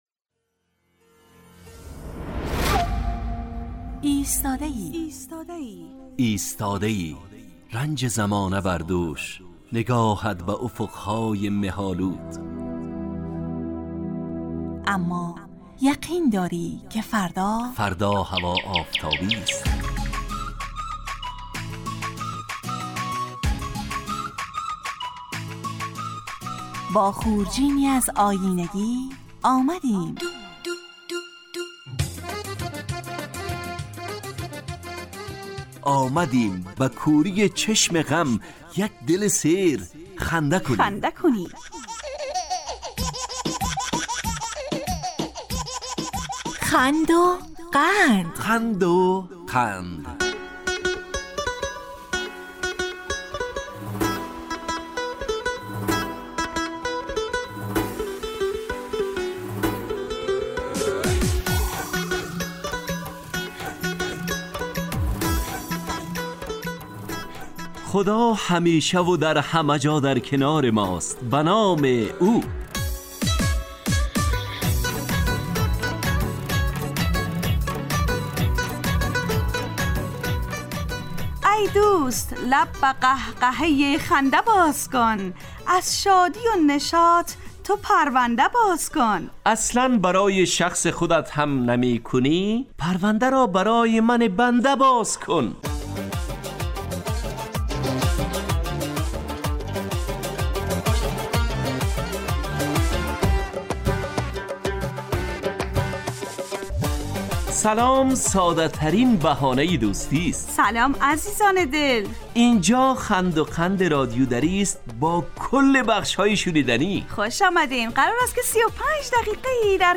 خند وقند برنامه ای طنز در قالب ترکیبی نمایشی است که هرجمعه به مدت 35 دقیقه در ساعت 9:15 به وقت ایران و 10:15 به وقت افغانستان از رادیو دری پخش میگردد.